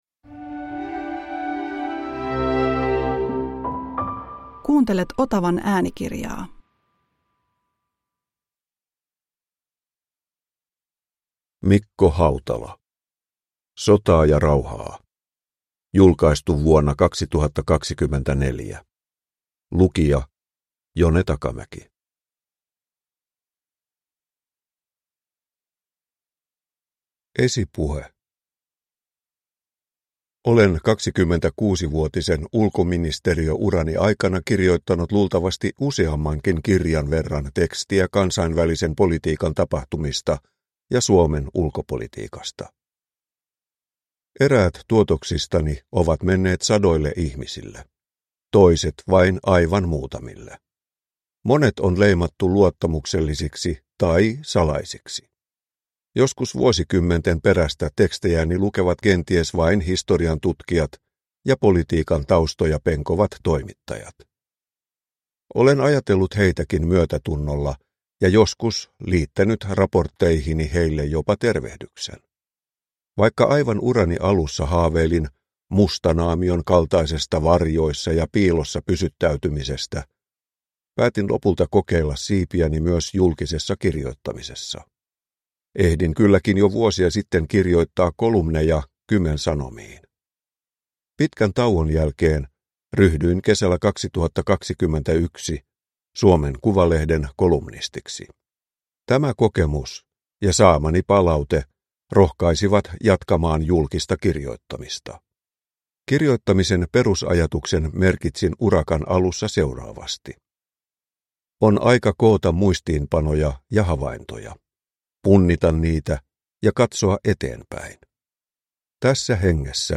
Sotaa ja rauhaa – Ljudbok